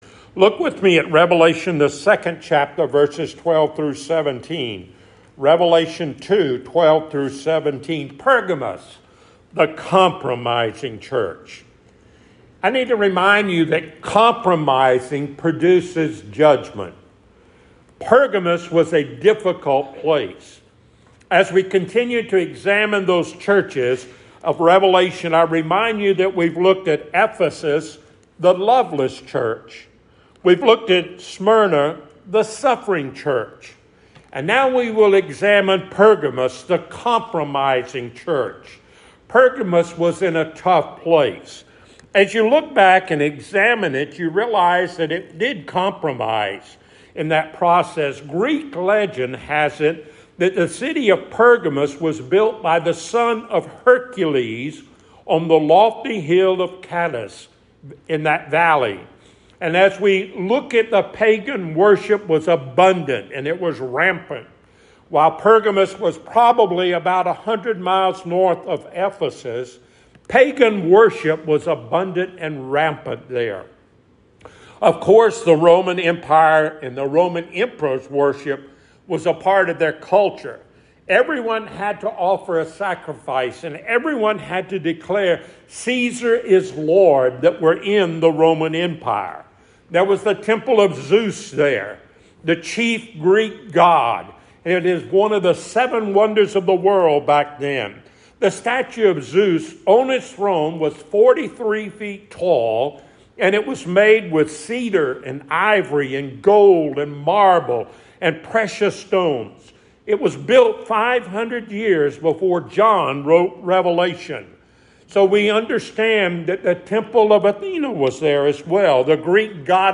Passage: Revelation 2:11-19 Service Type: Sunday Morning